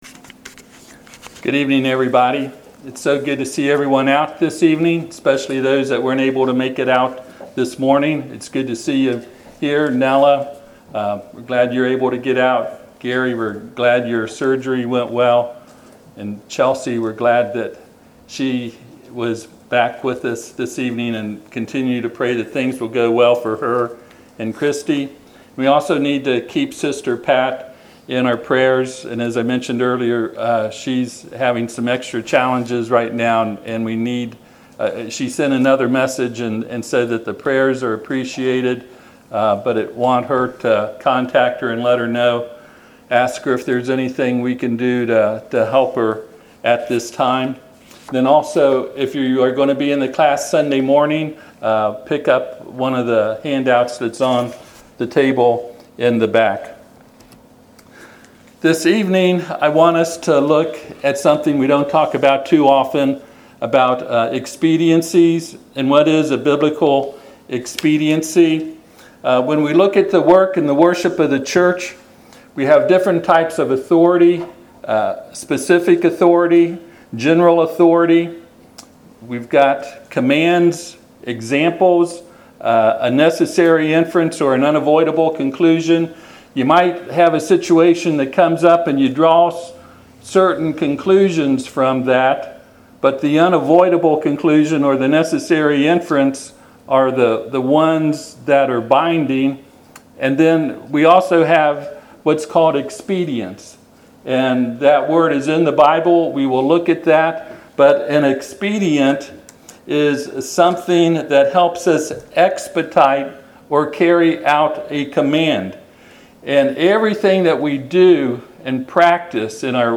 Passage: 1Corinthians 6:12 Service Type: Sunday PM « A Few Things God Expects Of Us